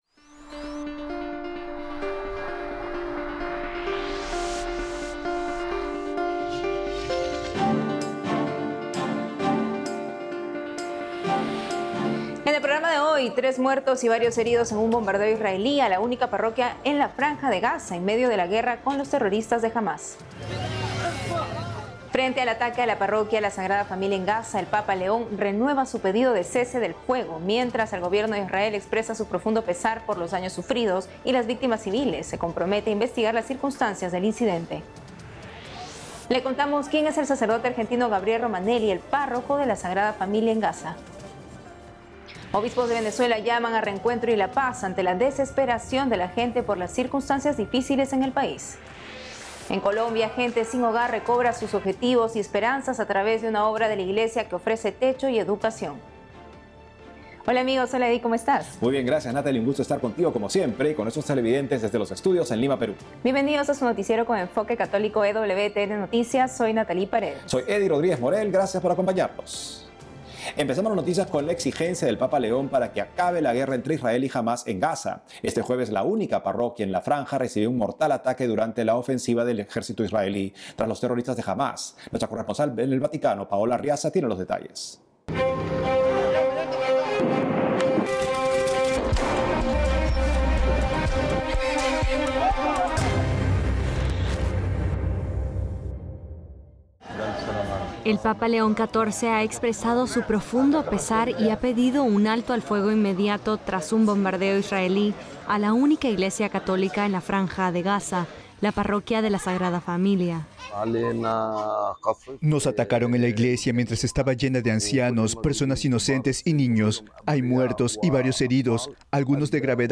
Noticiero diario producido exclusivamente para EWTN por la agencia ACI Prensa de Perú. Este programa informativo de media hora de duración se emite los sábados (con repeticiones durante la semana) y aborda noticias católicas del mundo y las actividades de Su Santidad Francisco; incluye también reportajes a destacados católicos de América del Sur y América Central.